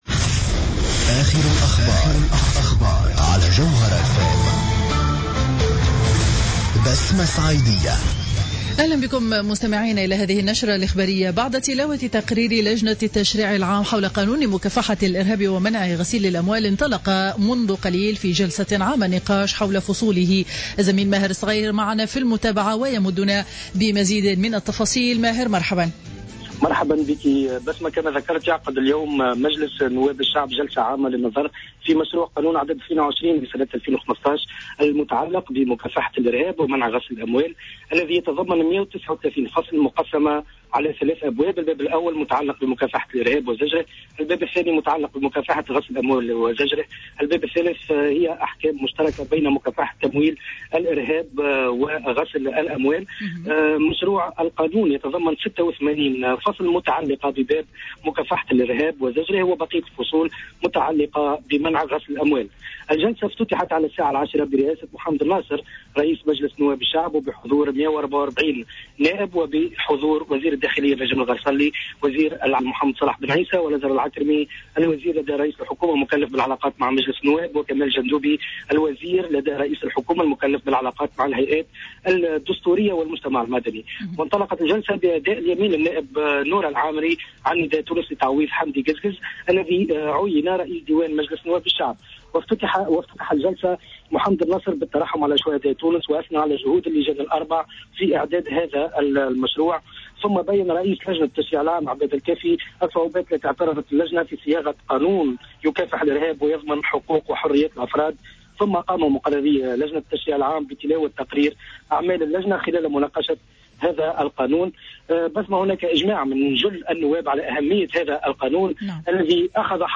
نشرة أخبار منتصف النهار ليوم الأربعاء 22 جويلية 2015